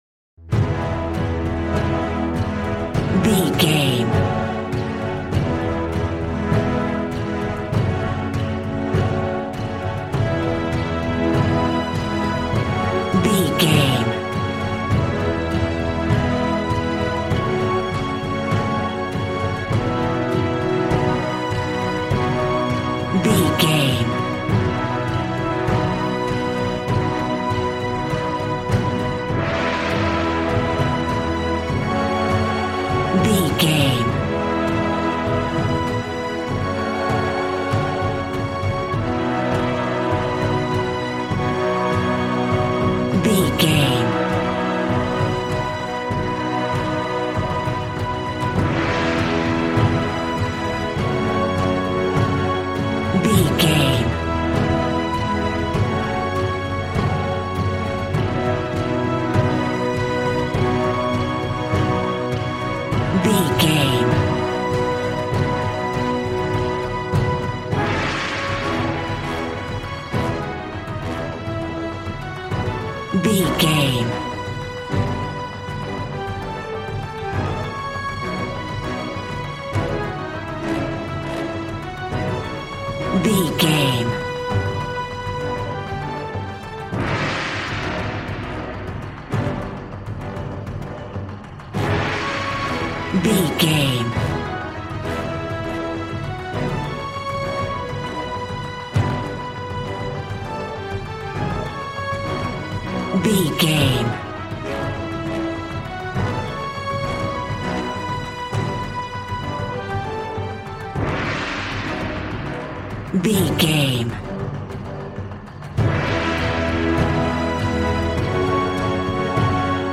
Action and Fantasy music for an epic dramatic world!
Ionian/Major
hard
groovy
drums
bass guitar
electric guitar